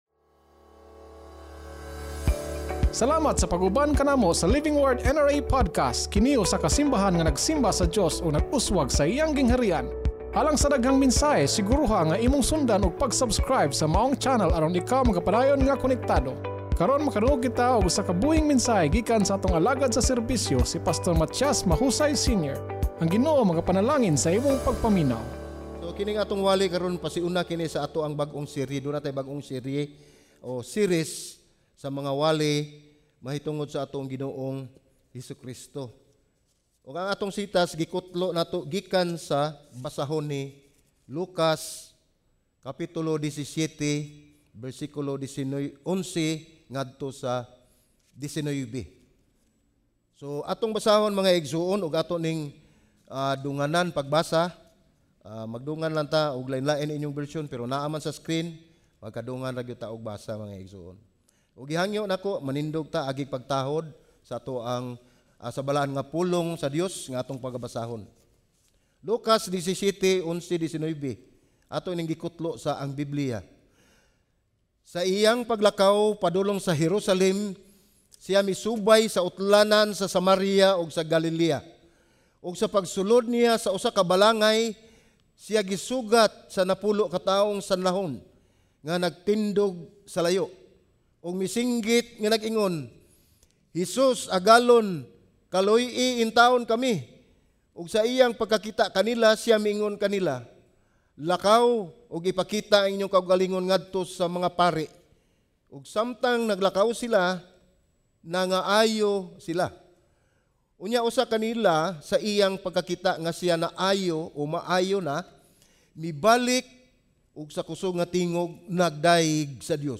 Luke Watch Listen Save Kung unsa ang mahimo ni Jesus alang kanato kinahanglan kanunay nga ubos sa kung kinsa si Jesus alang kanato. Sermon Title: SI HESUS MISMO ANG LABING MAHINONGDANON Scripture Text